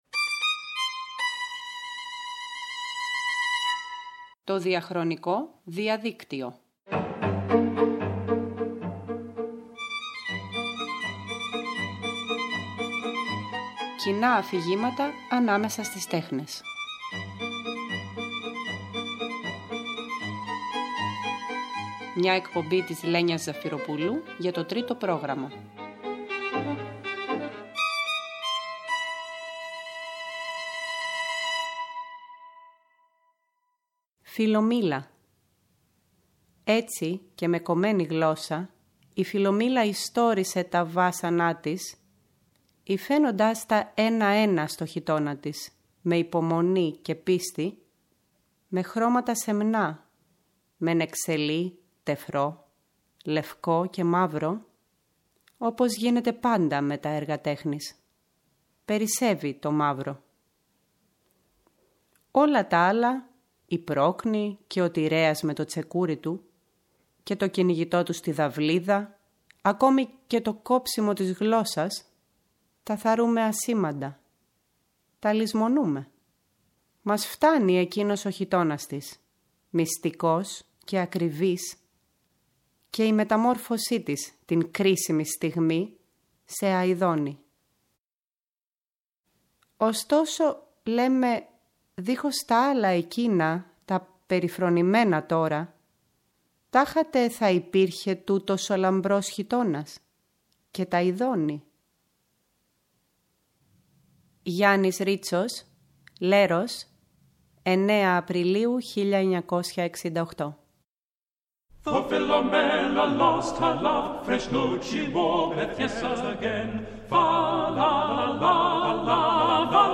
ακούμε ποίηση και μουσική εμπνευσμένη από τον Oβίδιο και διαβάζουμε από τη μετάφραση του Θεόδωρου Παπαγγελή.